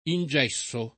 ingesso [ in J$SS o ]